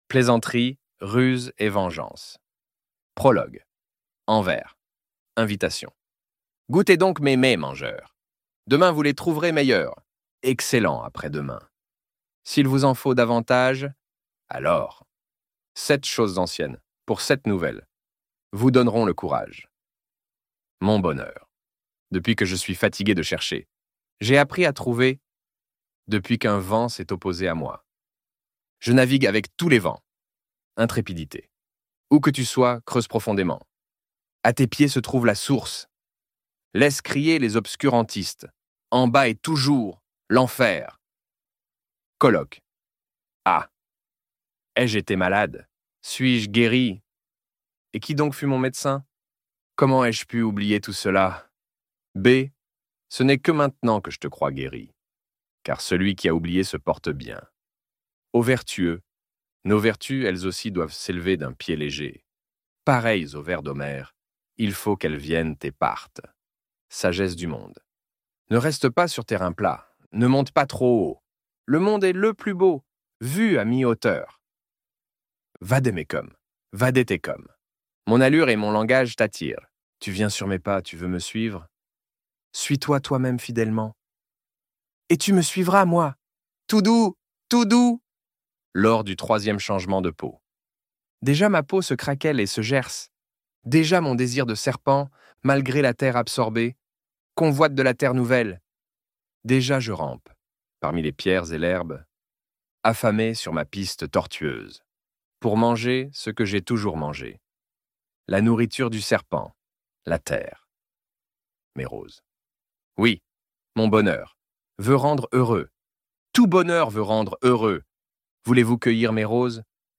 Le Gai Savoir - Livre Audio
Extrait gratuit